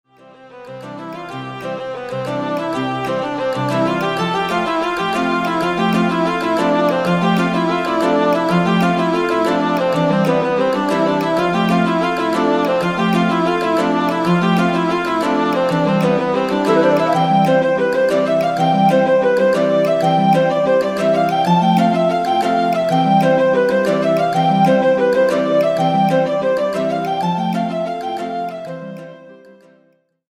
(音量注意)
アイリッシュに朝っぽいフレーズを盛り込んだ曲にしてみました。
ジグ(6/8拍子)の形式にしました。
笛はティンホイッスルや、ケルトフルートなどで厚みを持たせ
豪快にハープを盛り込んでみました（料理番組的解説ｗ